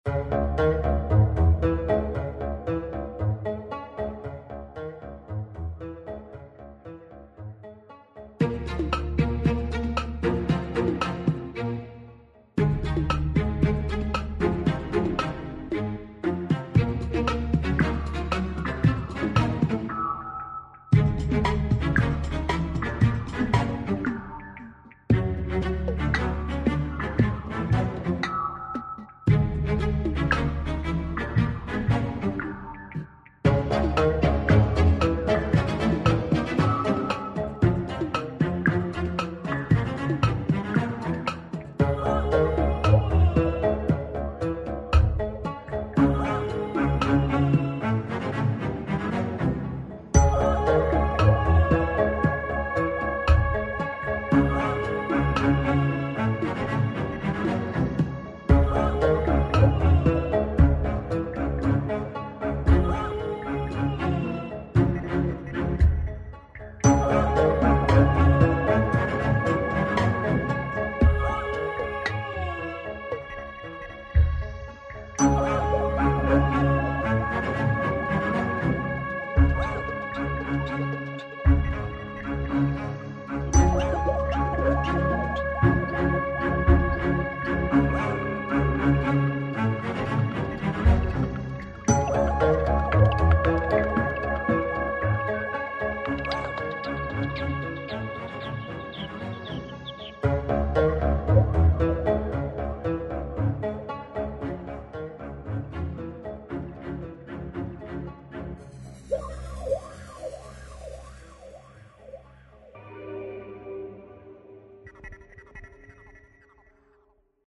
a moody and mysterious music track inspired by